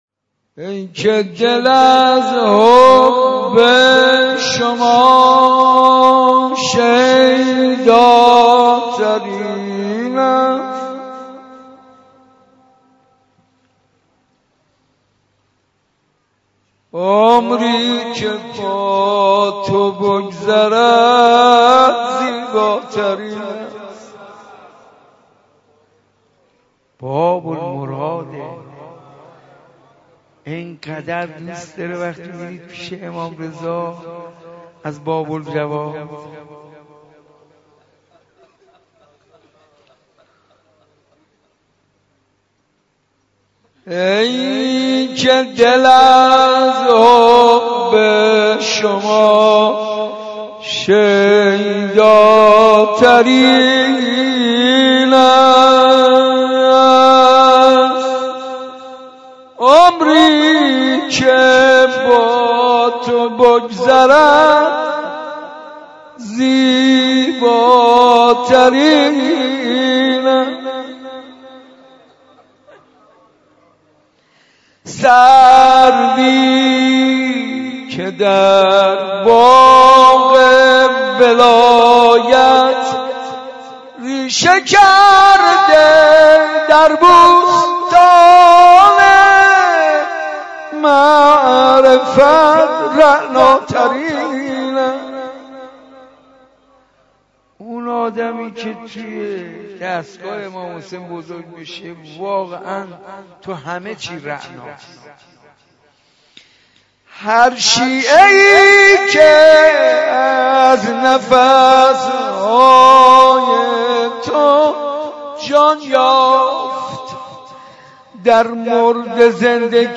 میلاد امام جواد و حضرت علی اصغر - مدح
مولودی حاج منصور ارضی